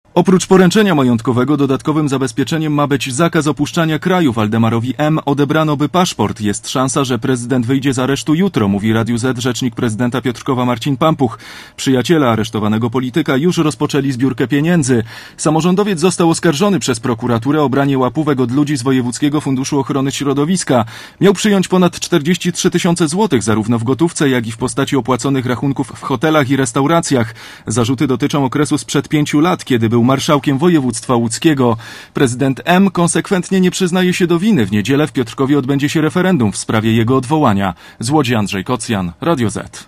Komentarz reportera Radia ZET